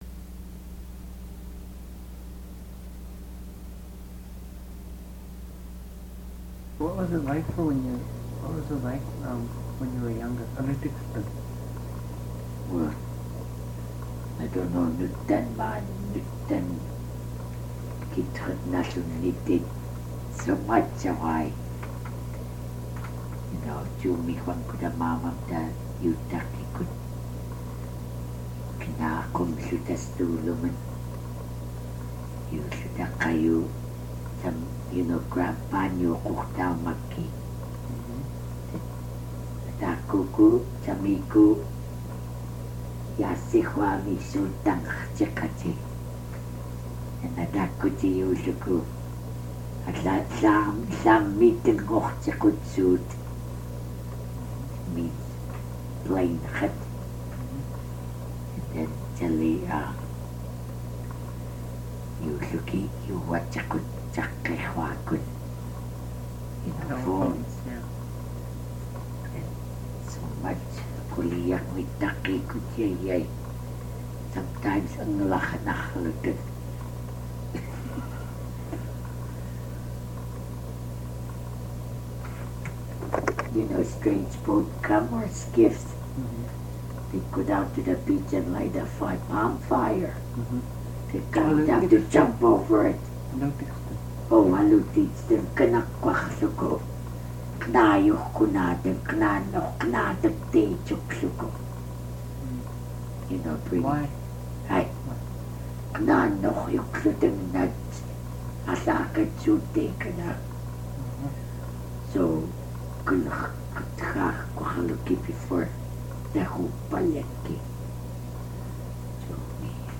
poor very quiet
great questions answers are in Alutiiq Location